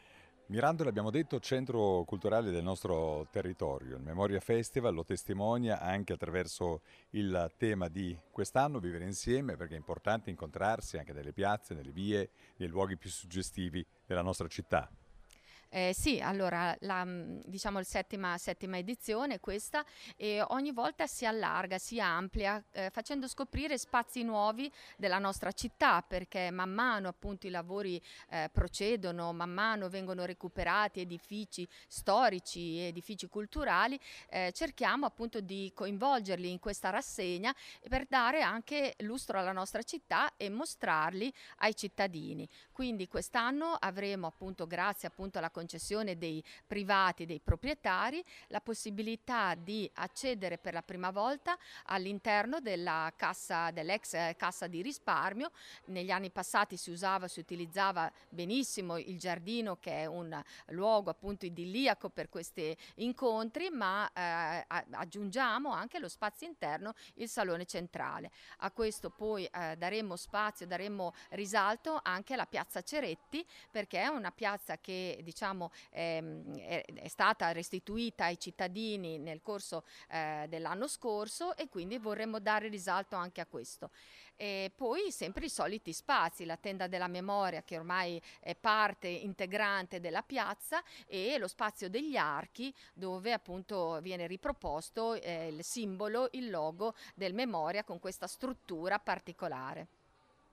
Ecco le dichiarazioni raccolte durante la giornata di presentazione:
Assessore alla cultura di Mirandola Marina Marchi: